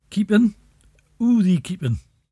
[kEEpun: oo thee kEEpun?]